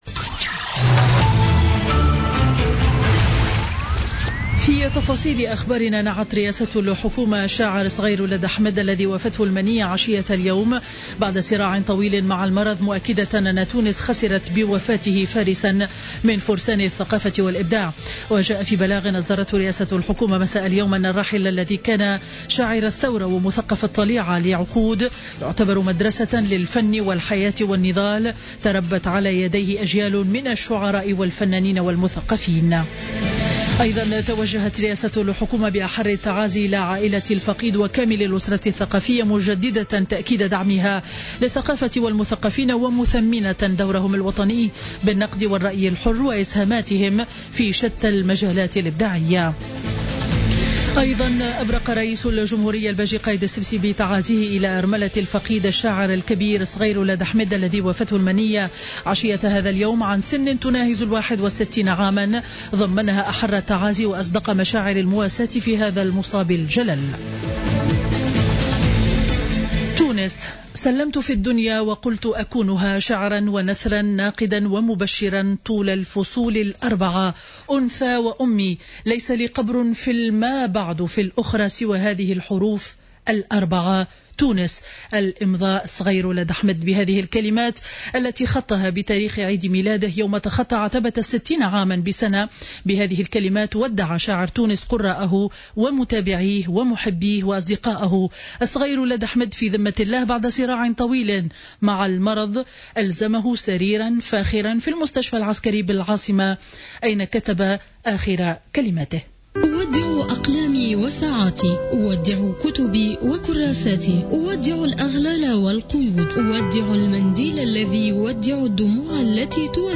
Journal Info 19h00 du Mardi 05 Avril 2016